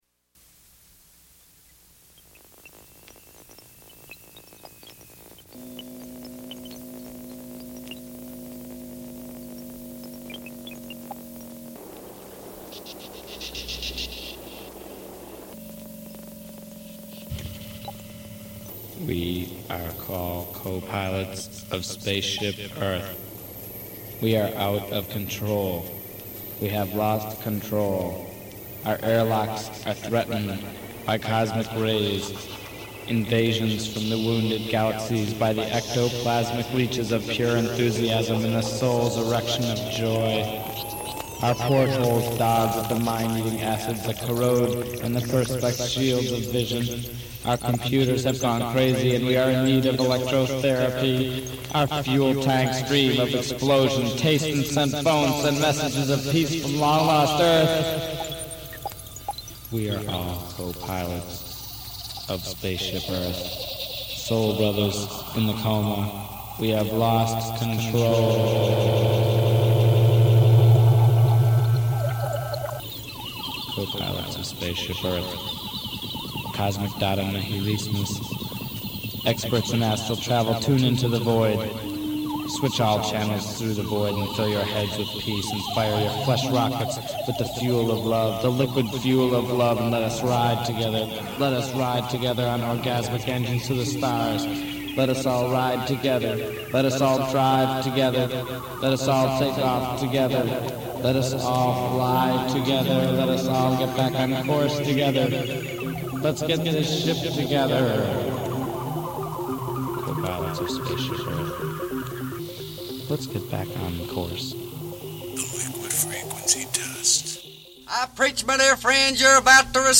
Genres : Eclectic , Pop , Rock